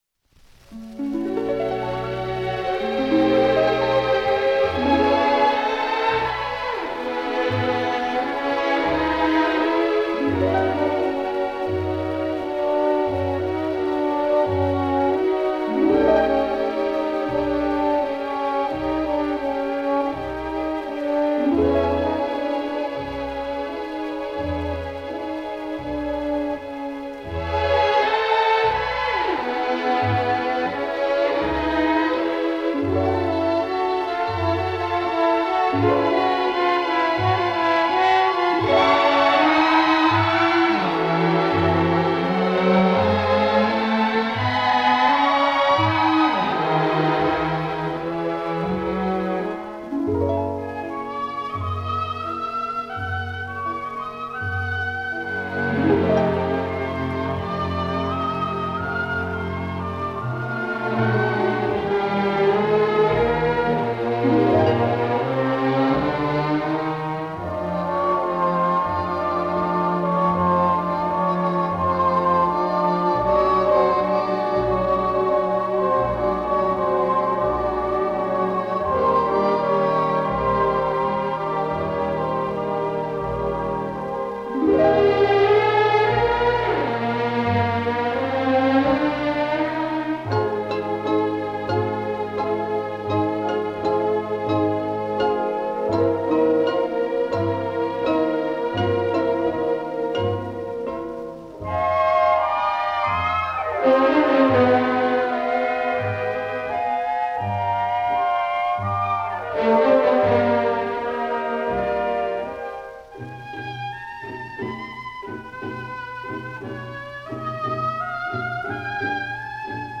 Оркестр